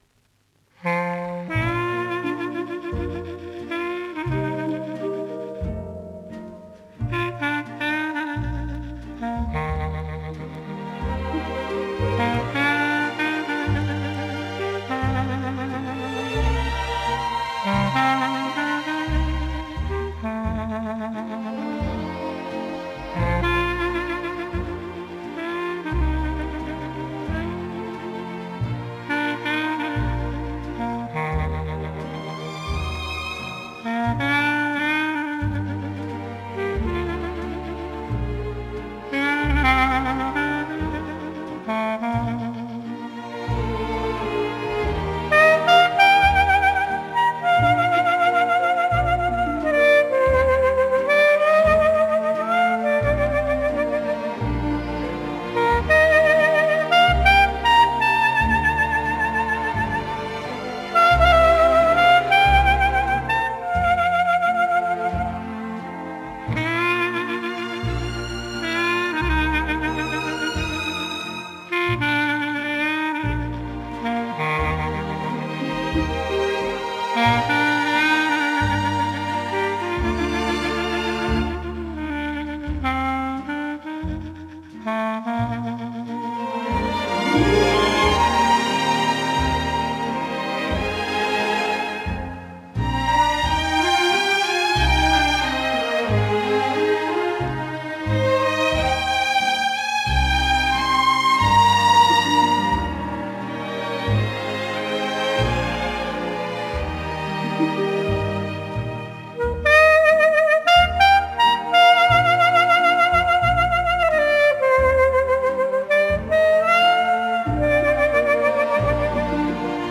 音乐风格: Jazz-Pop / Easy Listening